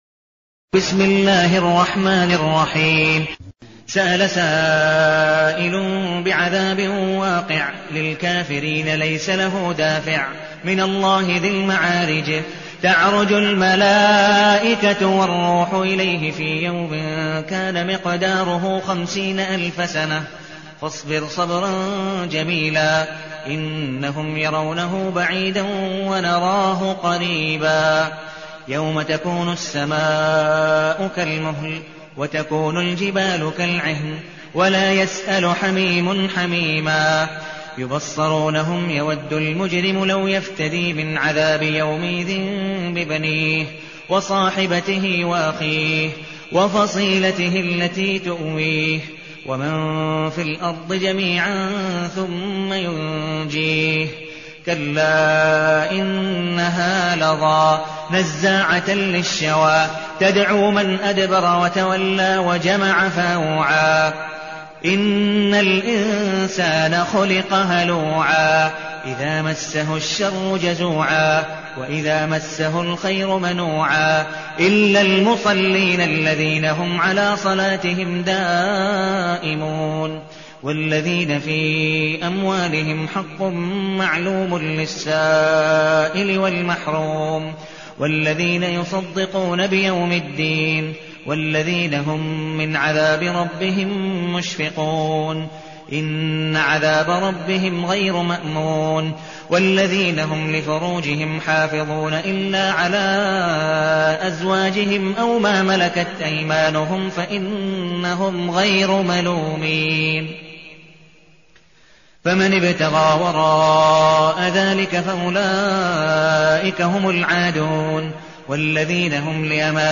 المكان: المسجد النبوي الشيخ: عبدالودود بن مقبول حنيف عبدالودود بن مقبول حنيف المعارج